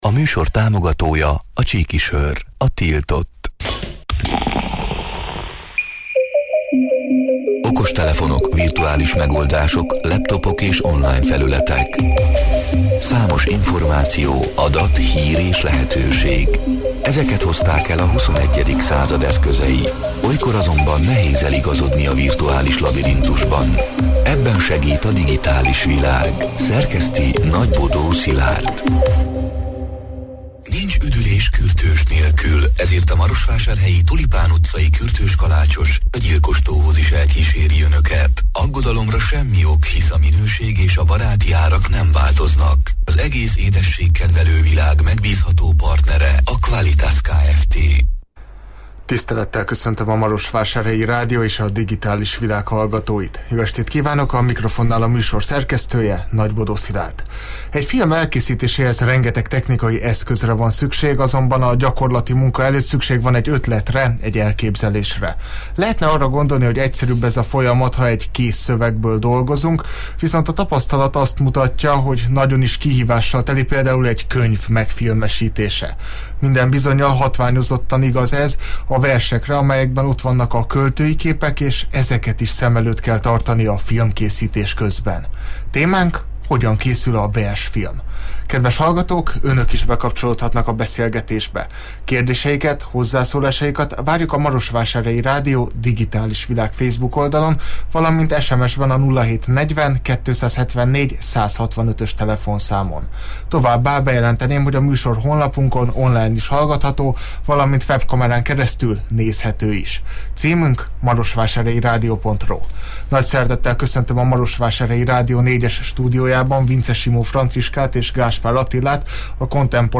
elhangzott: 2024. június 25-én, kedden este nyolc órától élőben